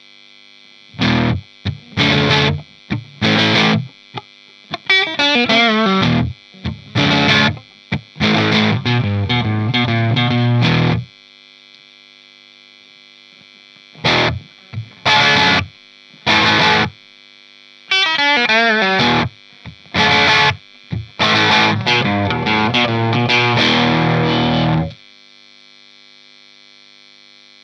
guitare (strat tokai ou melody maker ou westone thunder) -> ampli -> cab 2x12 greenback -> micro shure PG57 -> preamp micro -> numérisation (M audio 1010lt)
un petit bémol pour la prise de son sur le coté "pétillant" des samples en satu, en direct c'est pas "pétillant" de l'aigu.
a la melody maker. le micro bridge envoie bien!
crunch melody.mp3